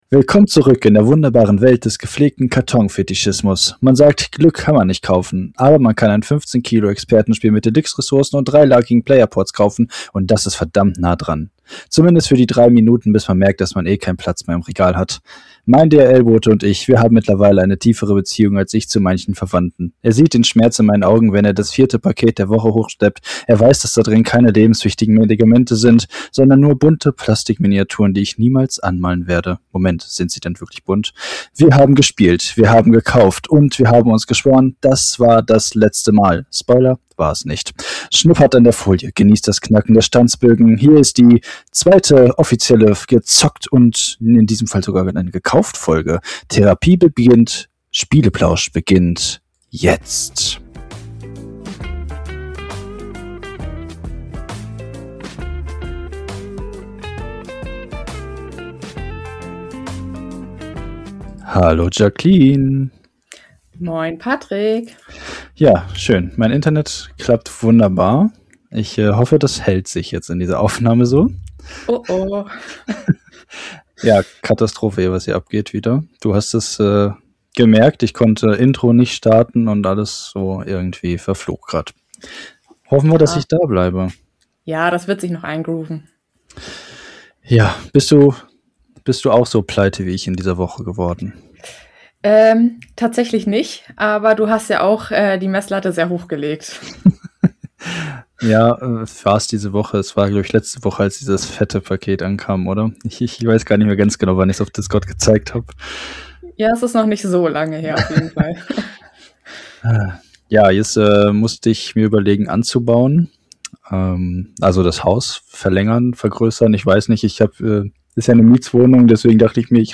Wir wollen euch mit unseren lieblichen Stimmen beglücken, ganz nach dem Motto: Legt euch zurück und entspannt.